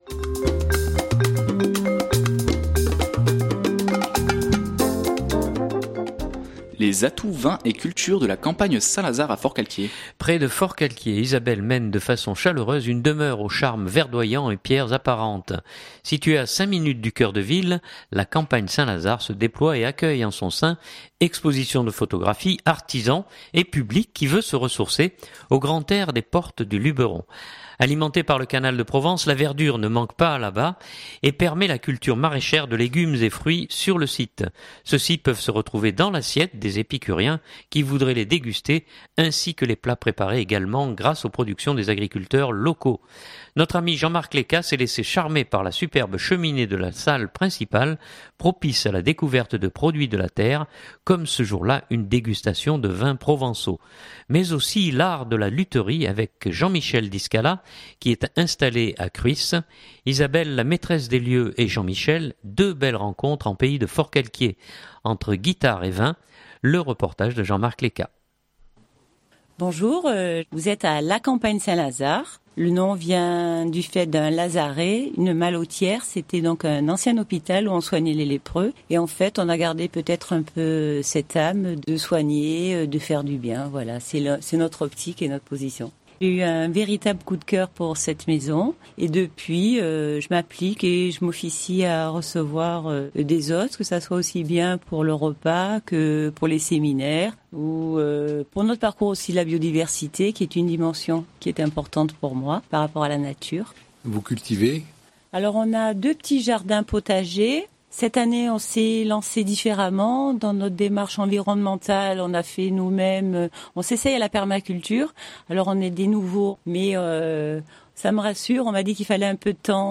Un reportage entre guitares et vins Ecouter ou télécharger Durée